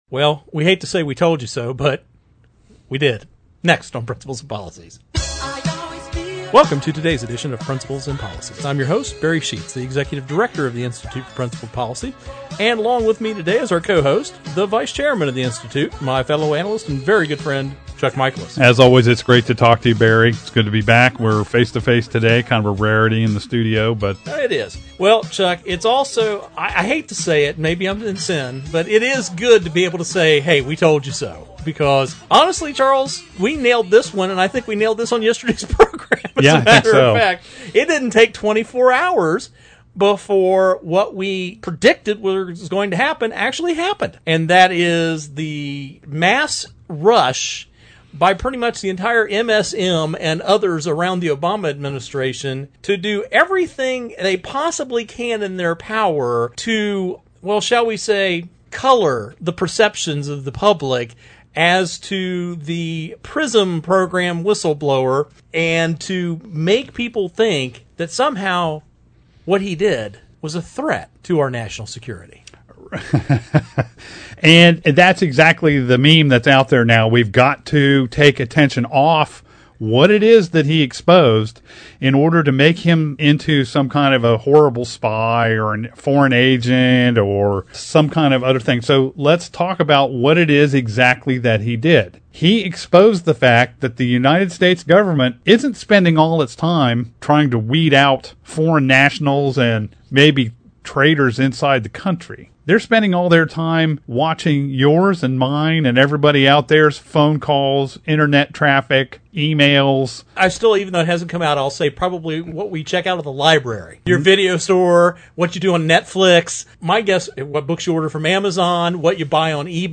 Our Principles and Policies radio show for Saturday March 11, 2017.